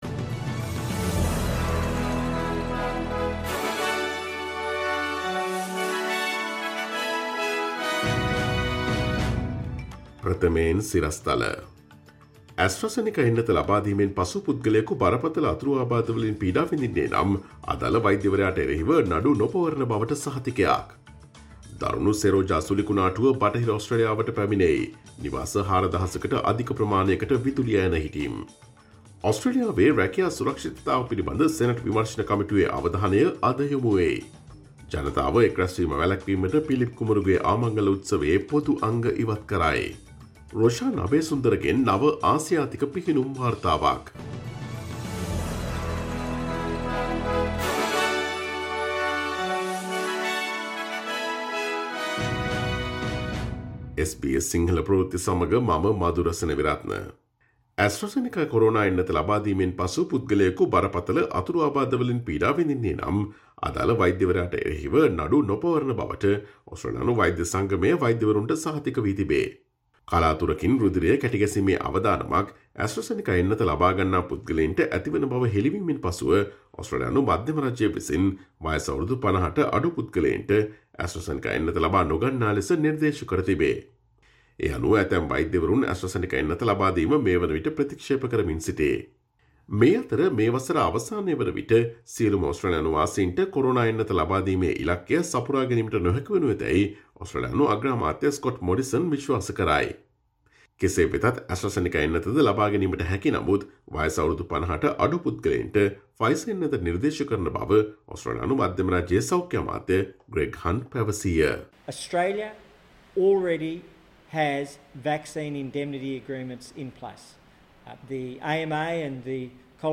Here are the most prominent Australian, International, and Sports news highlights from SBS Sinhala radio daily news bulletin on Monday12 April 2021.